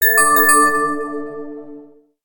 07187 fairy tales message ding
ding fairy fantasy intro magic message positive spell sound effect free sound royalty free Sound Effects